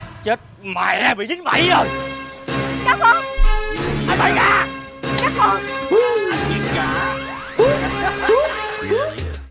Hiệu ứng âm thanh ghép video hay trên Tiktok